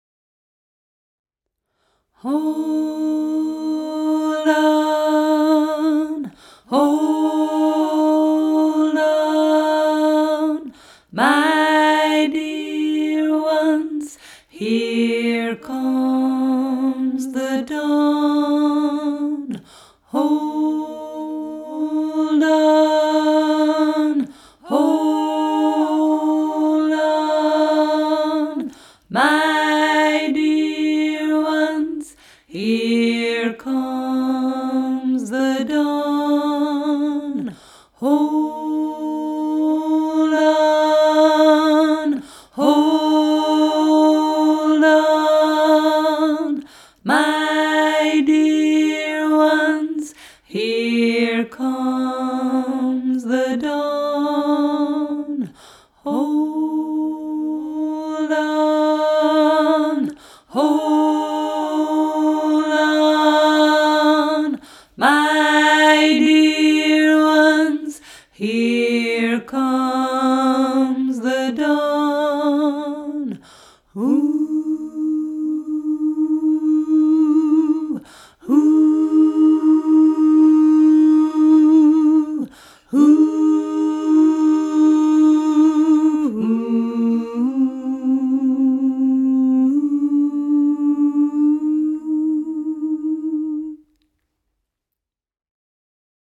Drone: